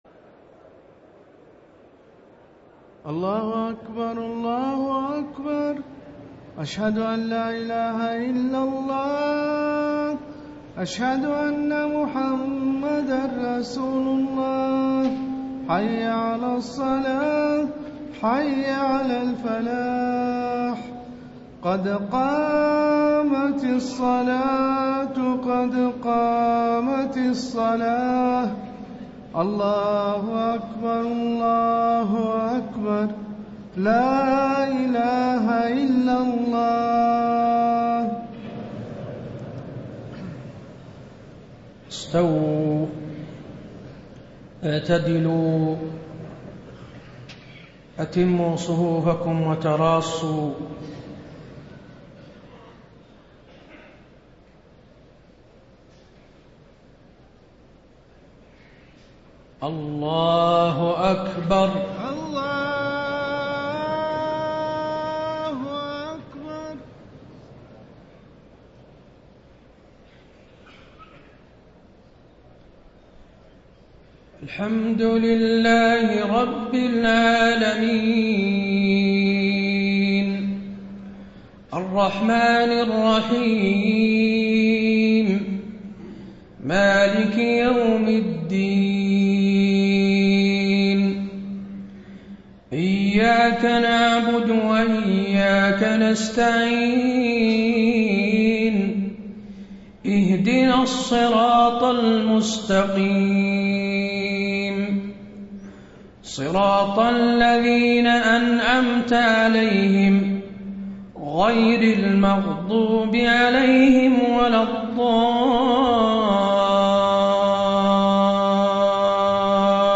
صلاة العشاء 5-4-1435هـ من سورة الواقعة > 1435 🕌 > الفروض - تلاوات الحرمين